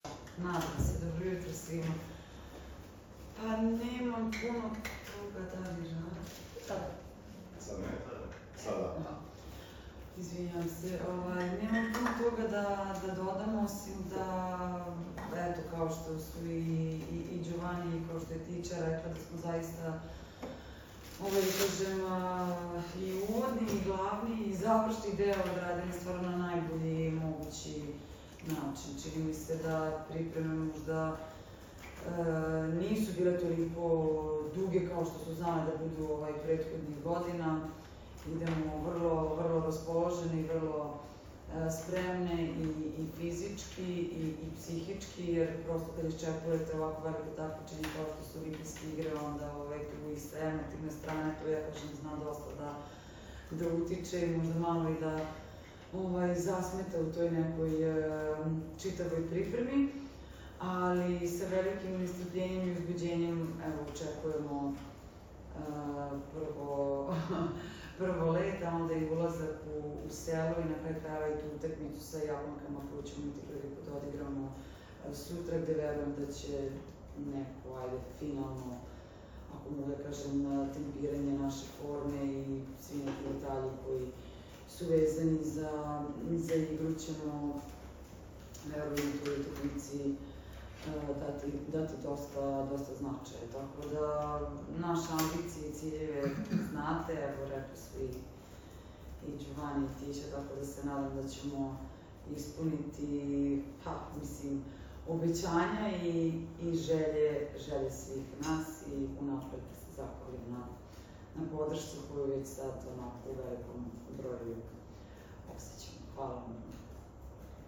Tim povodom je Olimpijski komitet Srbije na Aerodromu “Nikola Tesla” organizovao konferenciju za novinare.
Izjava Maje Ognjenović